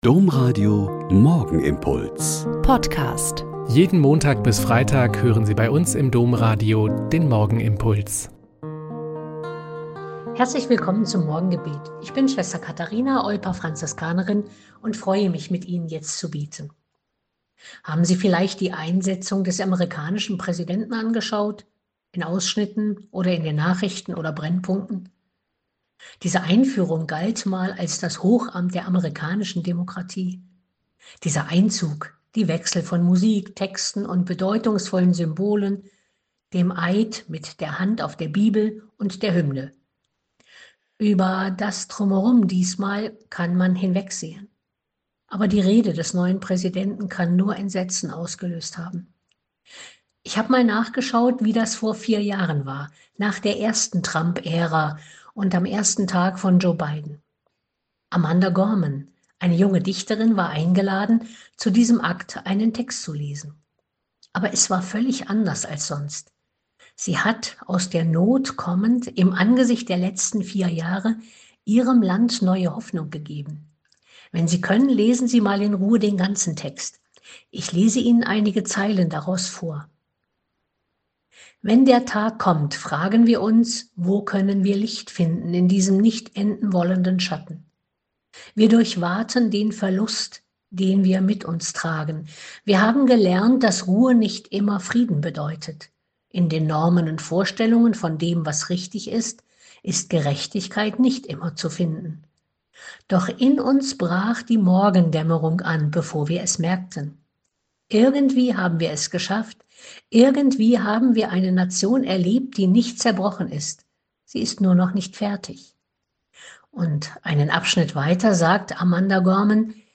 Mk 3,1-6 - Gespräch